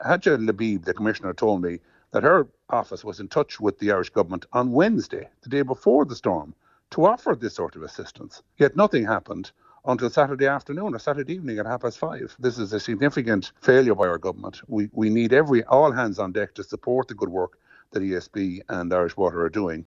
MEP Ciaran Mullooly says the delay is unacceptable: